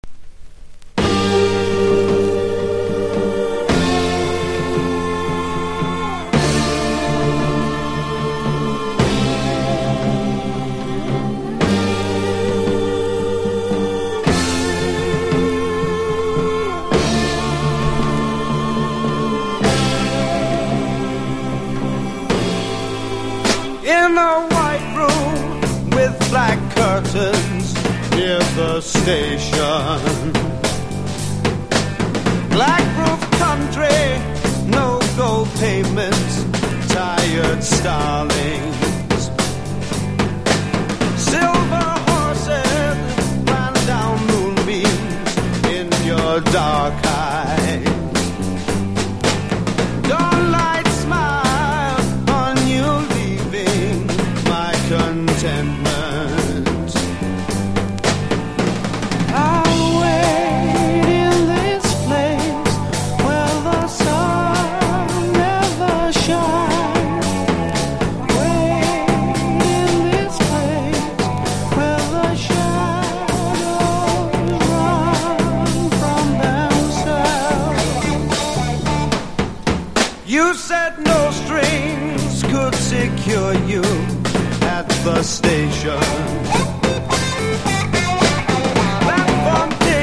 1. 60'S ROCK >
BLUES ROCK / SWAMP